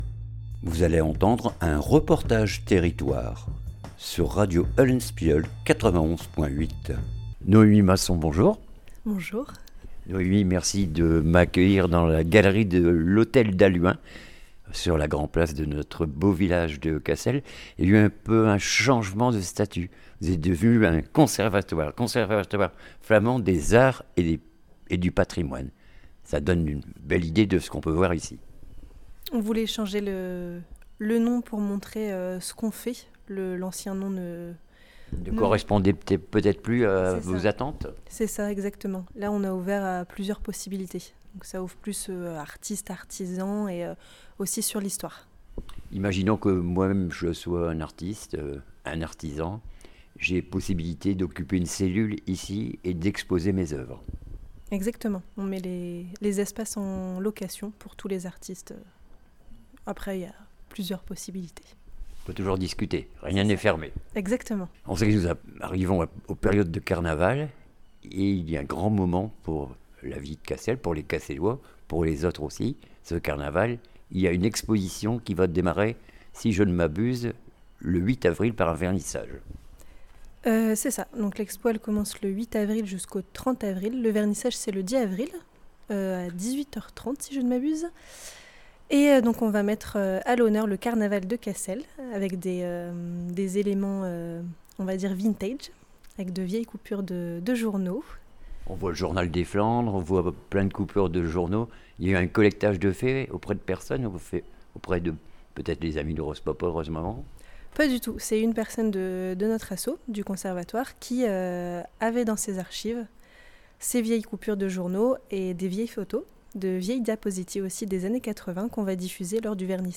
REPORTAGE TERRITOIRE GALERIE D HALLUIN CASSEL